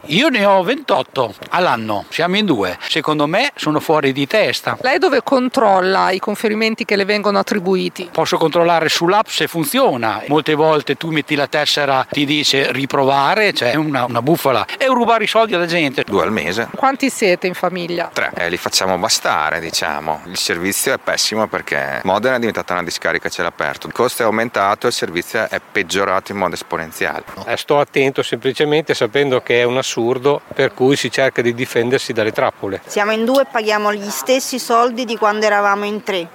Le interviste :